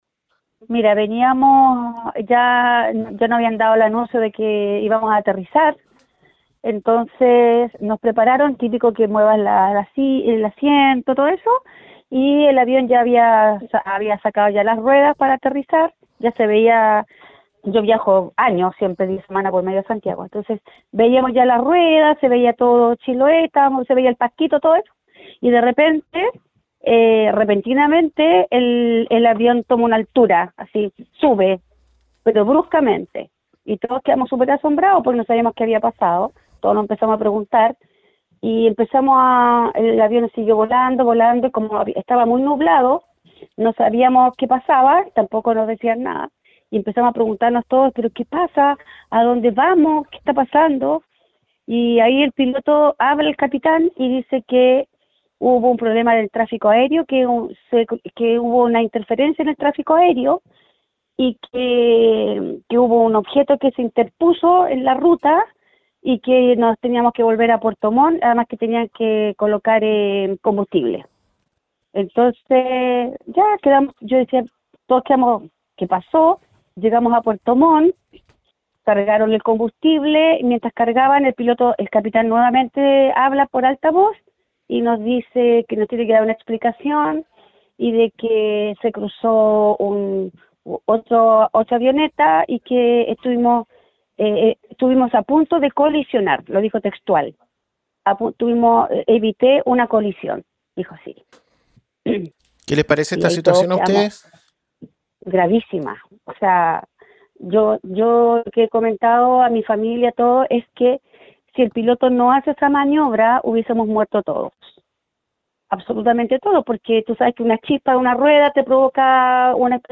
El relato de una pasajera del avión
Una pasajera del vuelo, que viajaba desde Santiago, contó a La Radio que el avión ya estaba por aterrizar cuando, de repente, “el avión toma una altura bruscamente”.